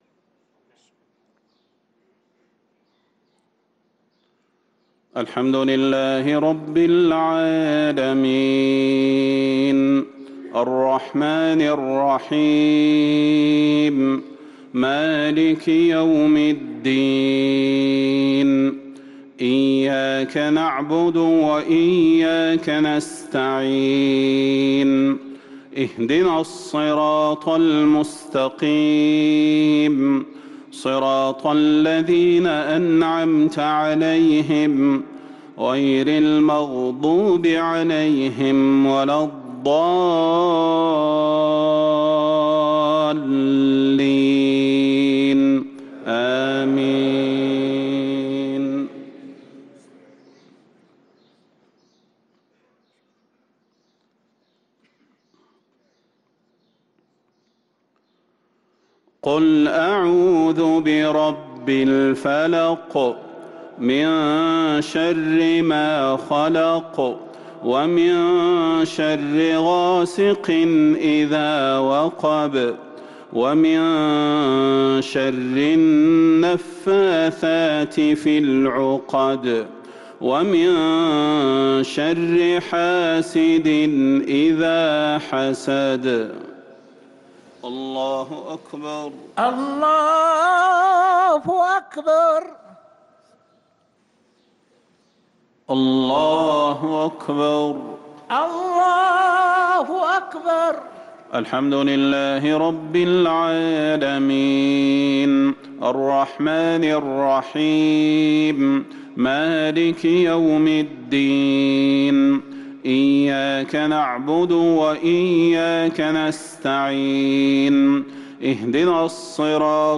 صلاة المغرب للقارئ صلاح البدير 23 شعبان 1444 هـ
تِلَاوَات الْحَرَمَيْن .